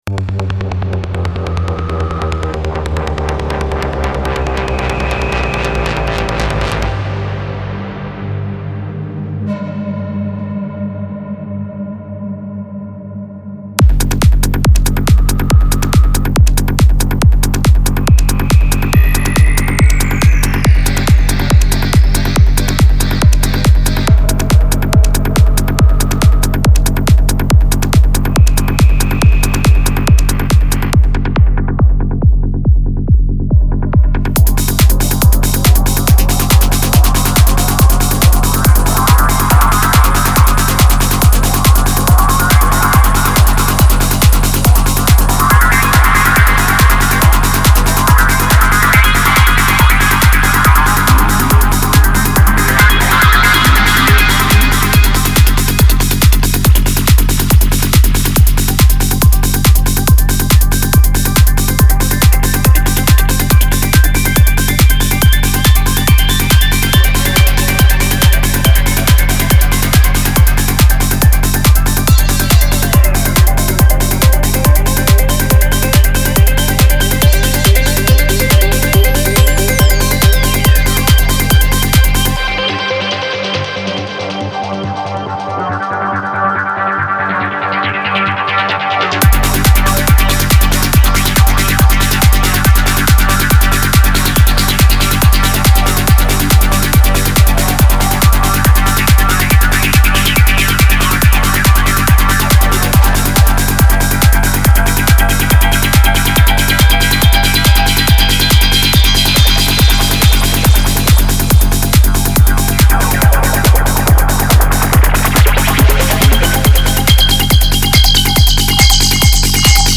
транс